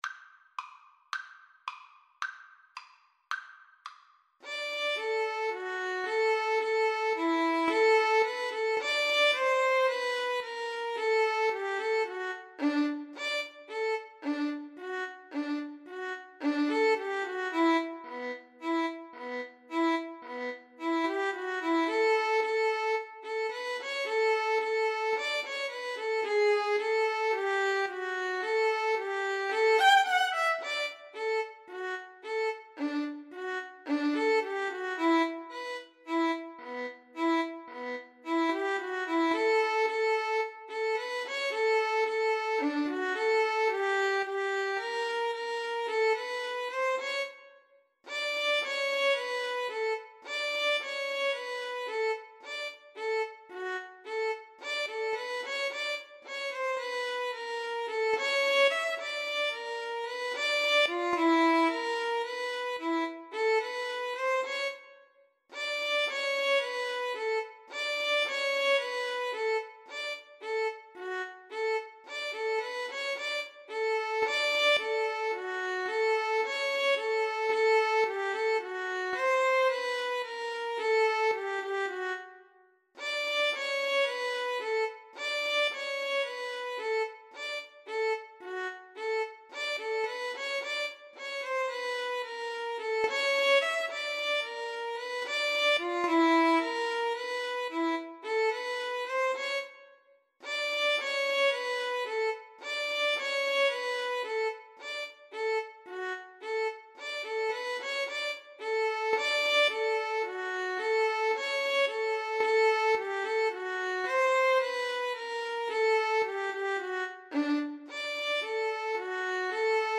2/4 (View more 2/4 Music)
Moderato allegro =110
Classical (View more Classical Violin Duet Music)